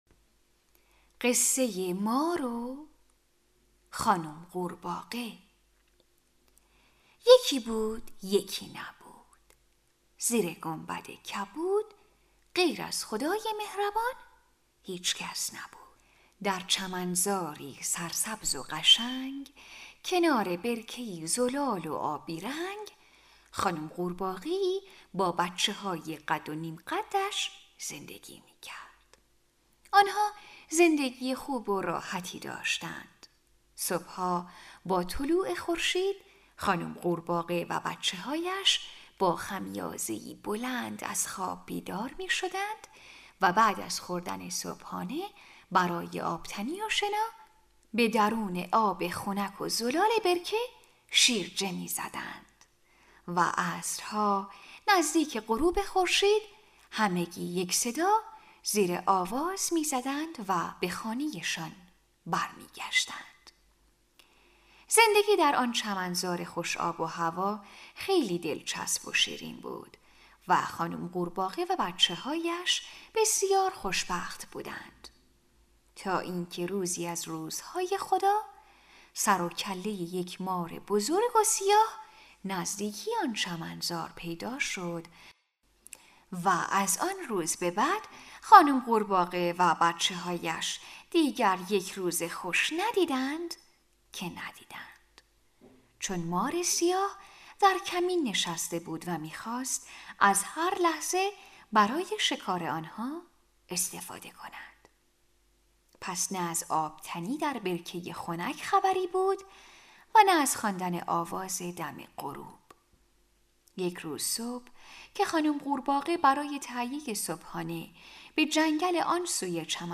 قصه های کودکانه